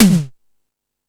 Hip Hop(25).wav